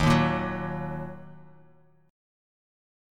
D#m Chord
Listen to D#m strummed